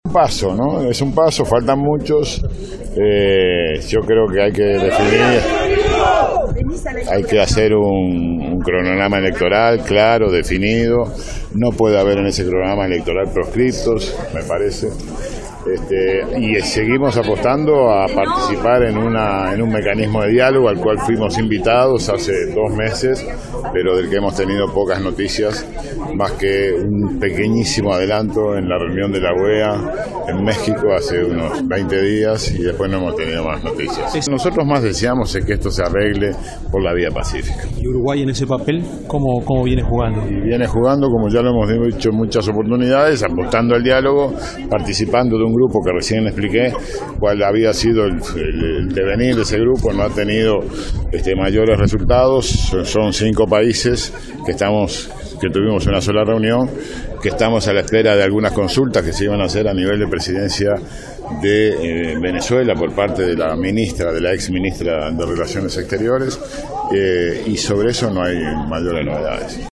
El canciller Nin Novoa sostuvo que la excarcelación de Leopoldo López en Venezuela “es un paso”, pero que resta definir un cronograma electoral claro sin proscriptos. Dijo a la prensa que Uruguay apuesta a la solución pacífica.